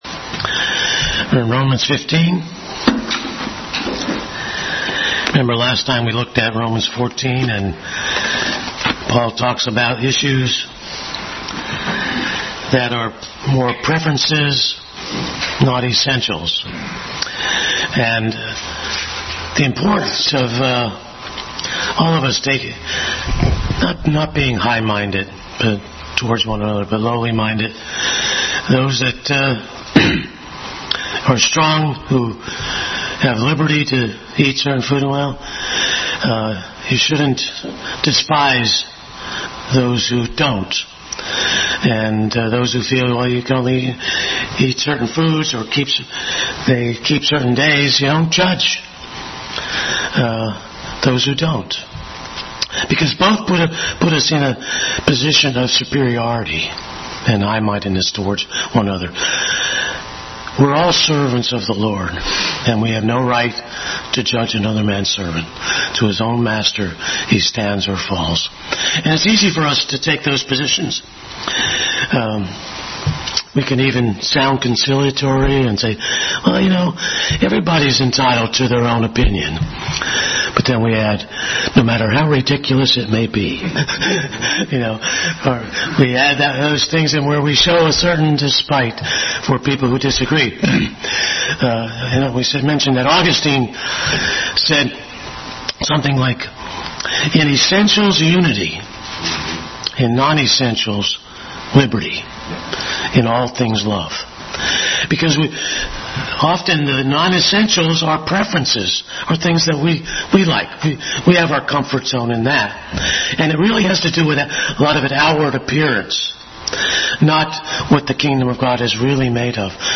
Bible Text: Romans 15:1-13 | Adult Sunday School continued study in the book of Romans.
Romans 15:1-13 Service Type: Sunday School Bible Text